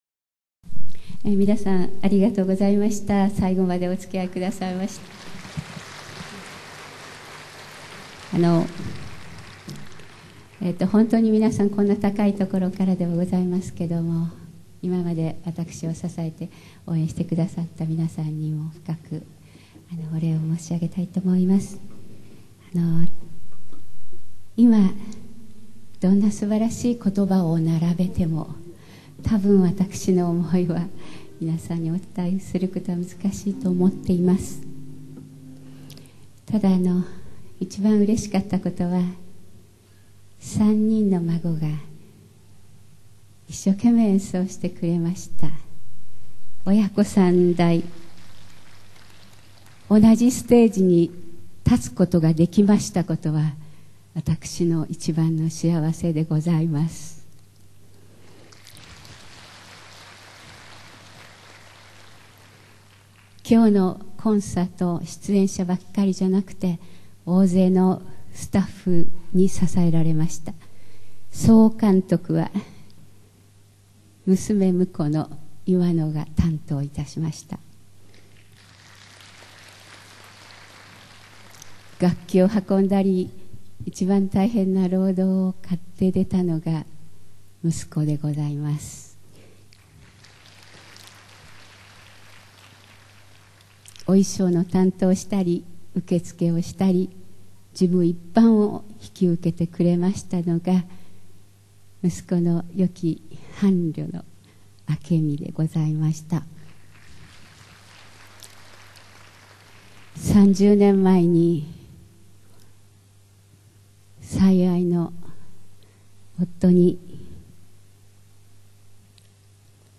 邦楽と洋楽のジョイントコンサート